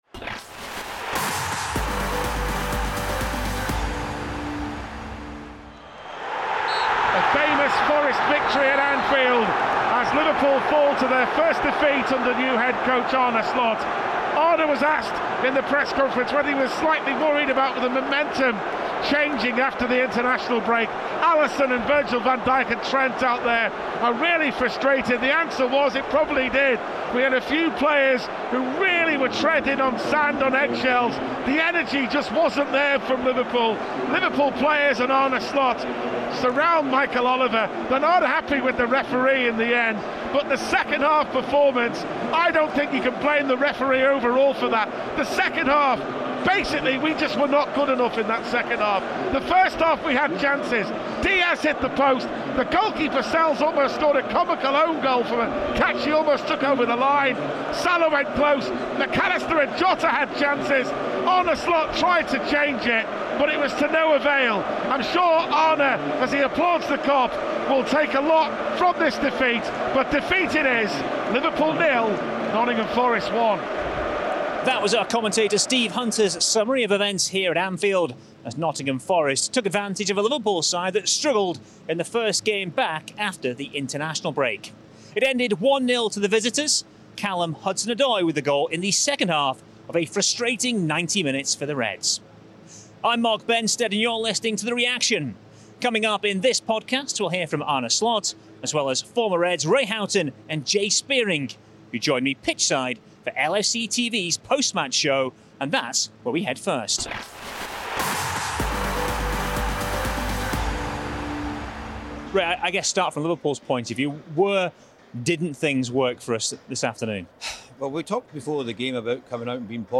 pitch-side for LFCTV's post-match show